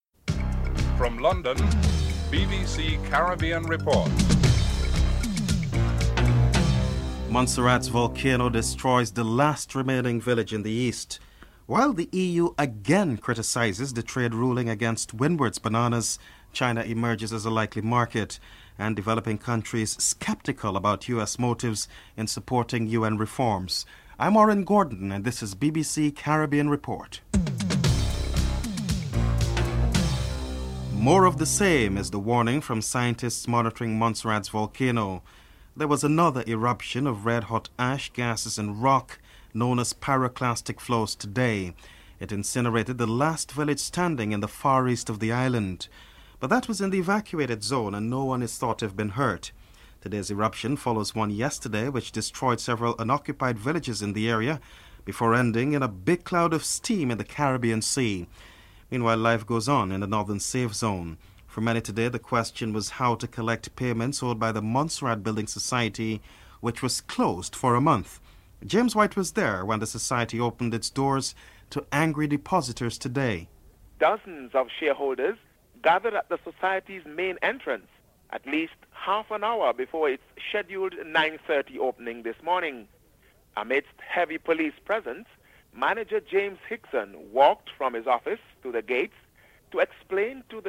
1. Headlines (00:00-00:30)
President Bill Clinton and Kofi Annan, Secretary-General of the United Nations are interviewed.